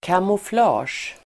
Ladda ner uttalet
Uttal: [kamofl'a:sj]